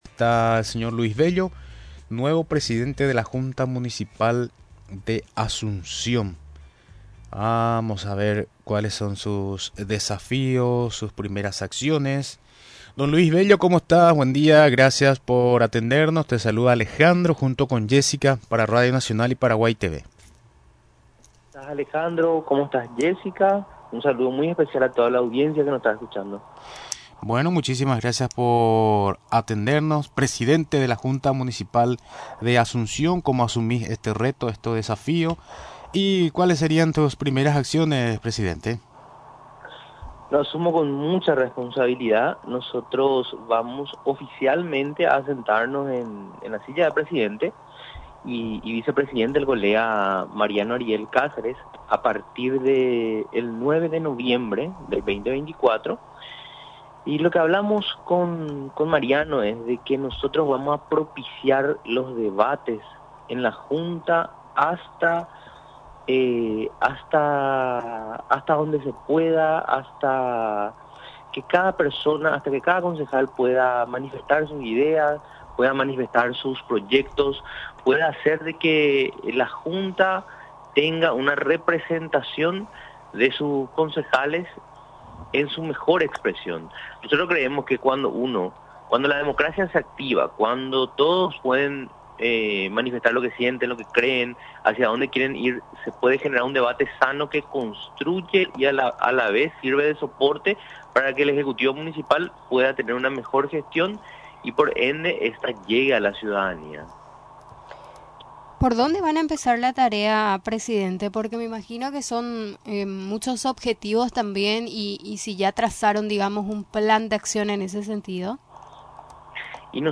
Durante la entrevista en Radio Nacional del Paraguay, aseguró que llega para trabajar, además de apoyar al intendente capitalino.